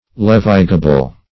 Meaning of levigable. levigable synonyms, pronunciation, spelling and more from Free Dictionary.
Search Result for " levigable" : The Collaborative International Dictionary of English v.0.48: Levigable \Lev"i*ga*ble\ (l[e^]v"[i^]*g[.a]*b'l), a. [See Levigate , v. t.] Capable of being levigated.